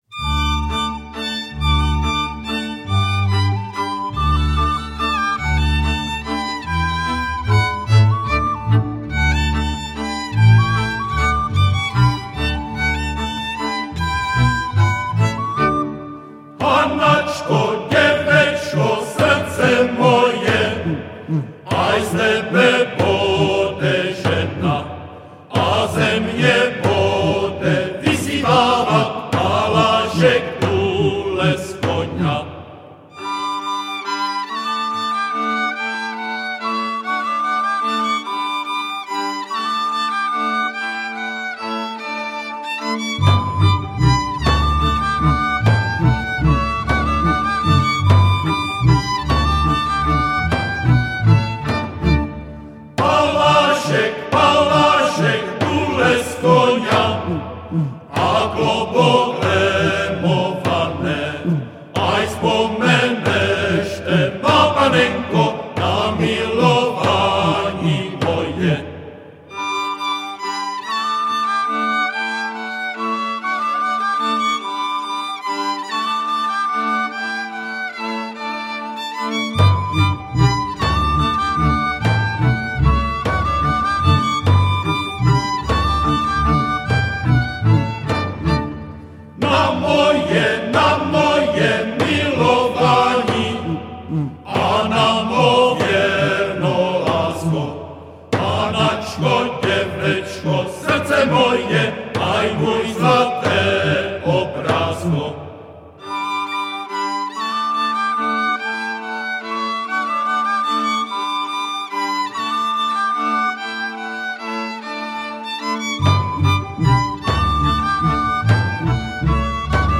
Žánr: World music/Ethno/Folk
písní a capella